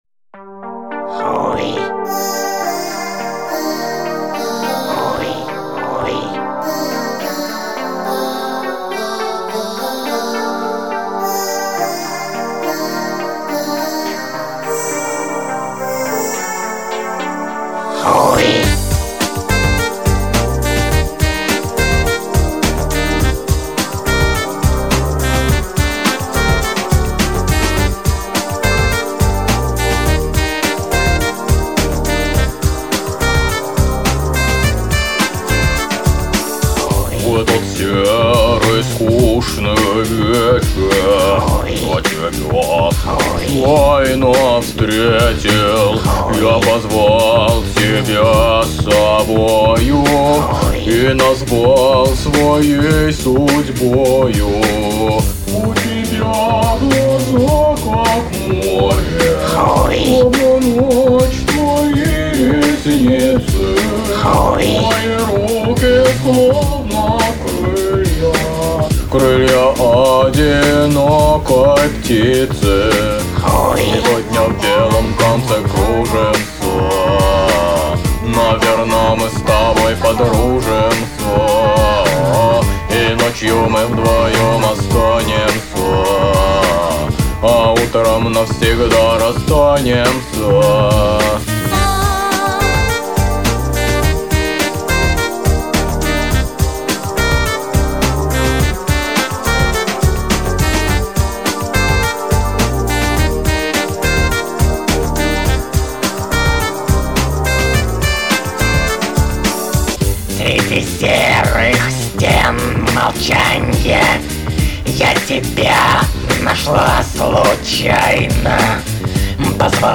в конце он сказал его и я подумал сделать его для ритмичности во всей песни......